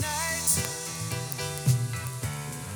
That’s the sound of the left channel from the sample Audio above:
I’ve also used an inverse RIAA to bring up the bass a bit.
By the way, the FM signal has only -31 to 36 dB, instead of -19 dB.